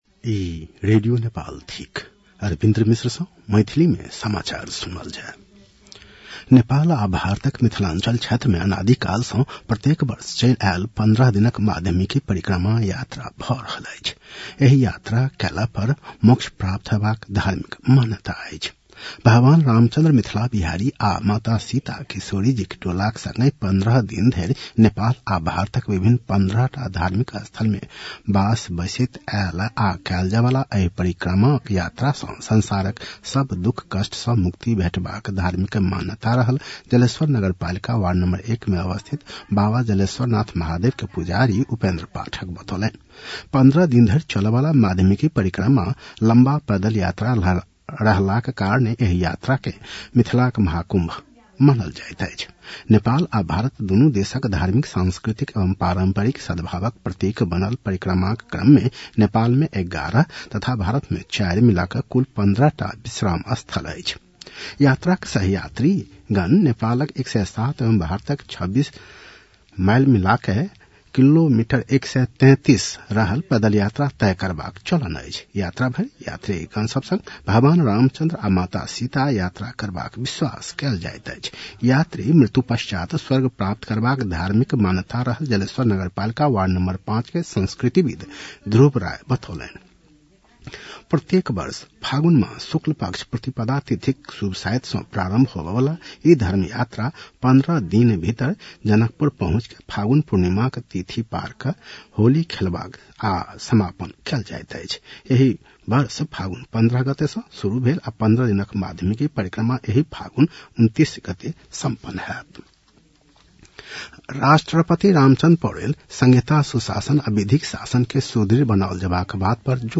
मैथिली भाषामा समाचार : २८ फागुन , २०८१